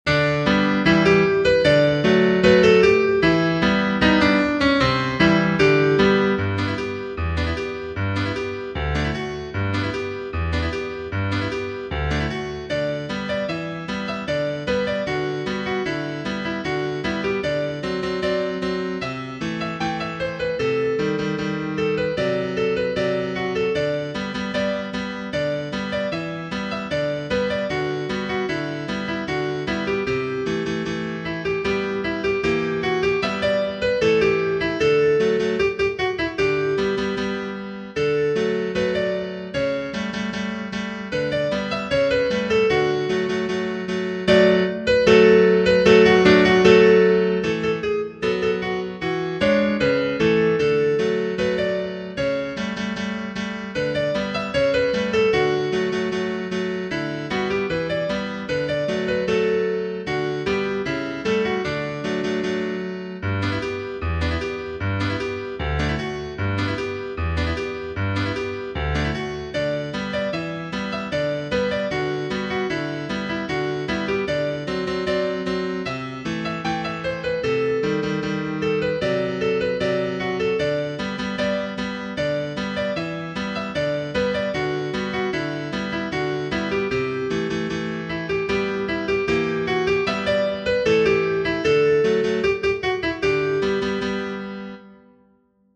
Genere: Moderne